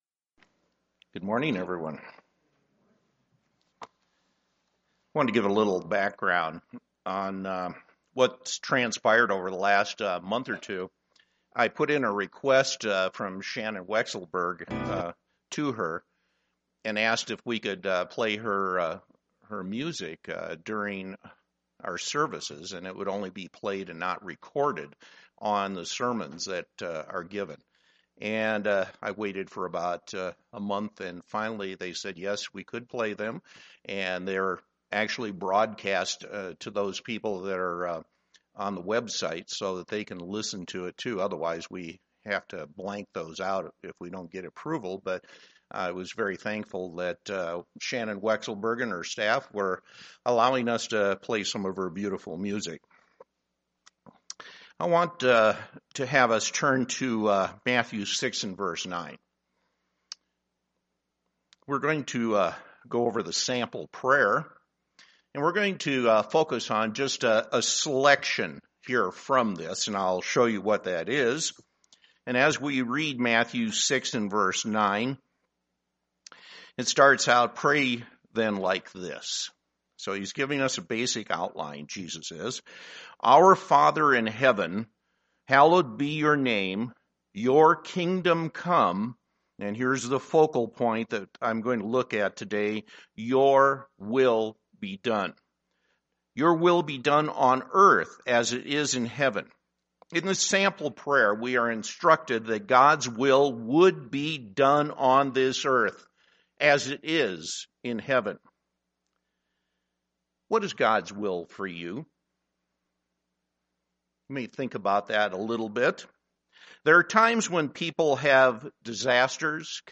UCG Sermon Studying the bible?
Given in Denver, CO